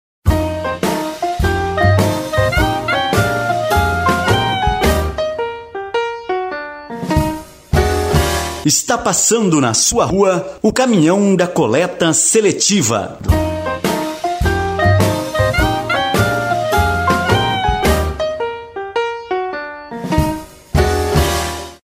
AVISO SONORO DA COLETA SELETIVA